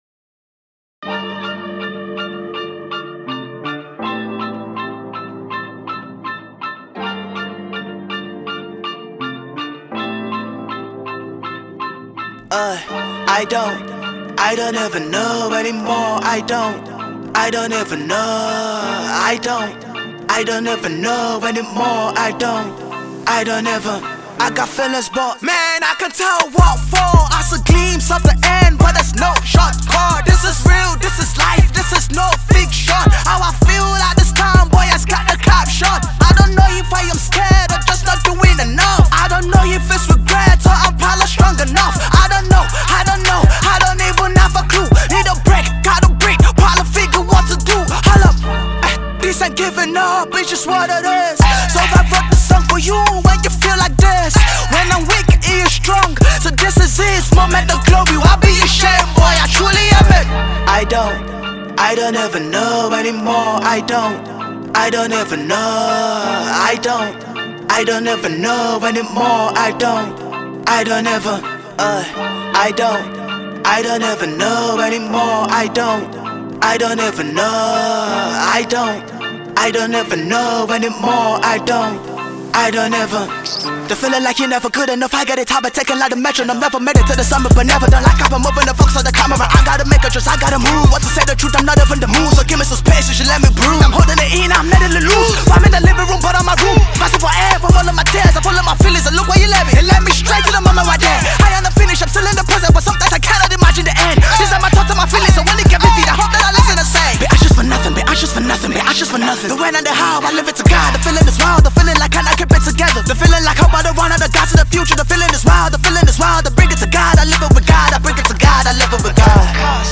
Classic Trap song